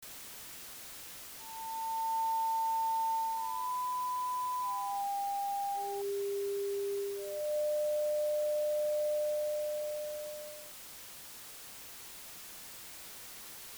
That last one is scary  tongue
eartone_alien.mp3